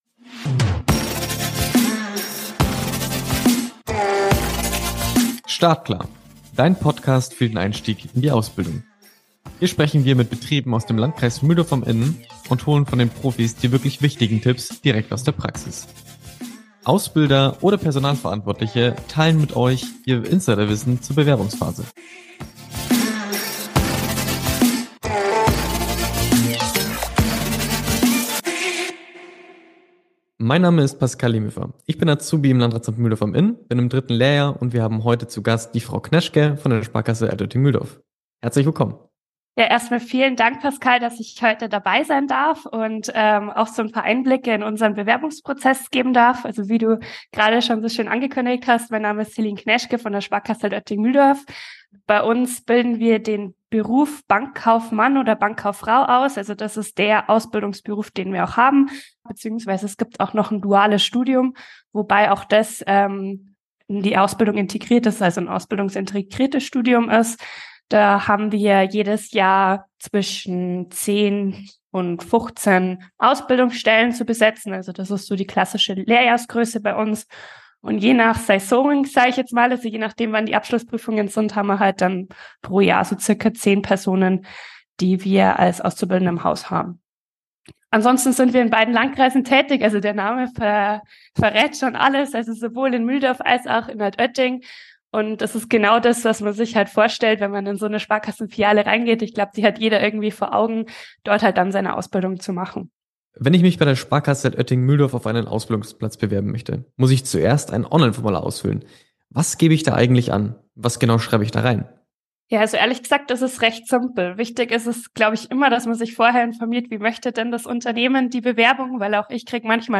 im Gespräch mit der Sparkasse Altötting-Mühldorf
Hier geht es zum gesamten Interview